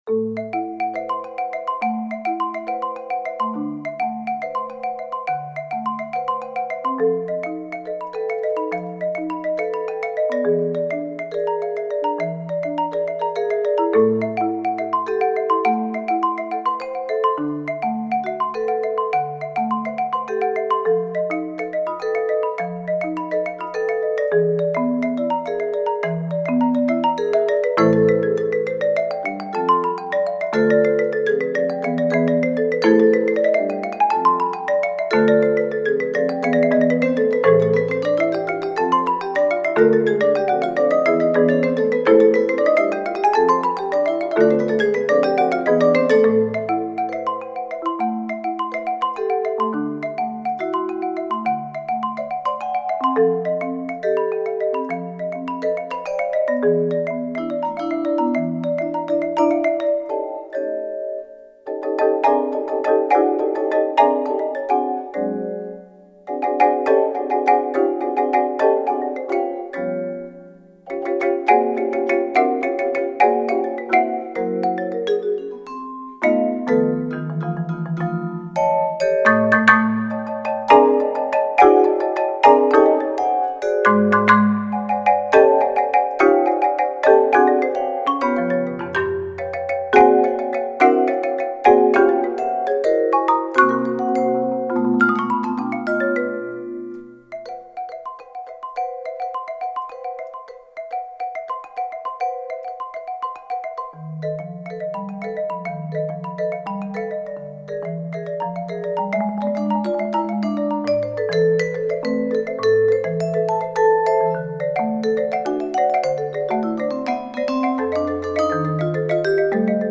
Voicing: Mallet Trio